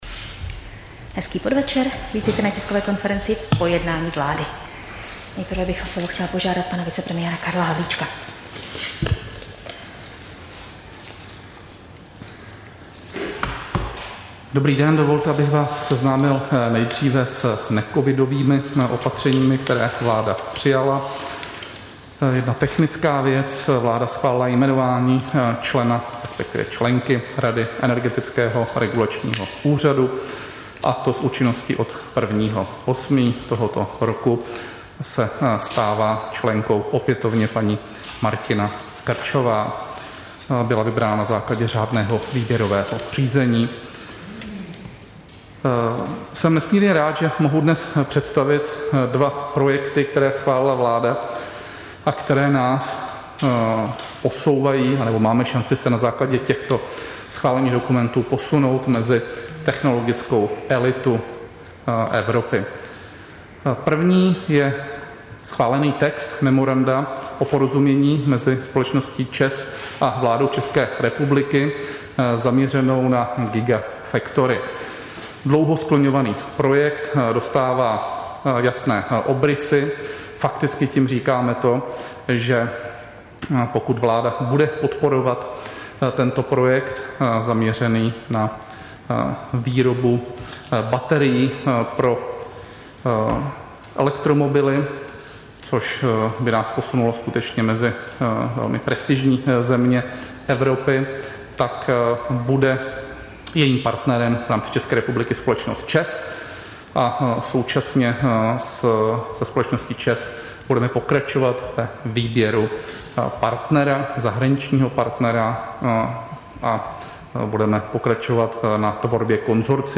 Tisková konference po jednání vlády, 26. července 2021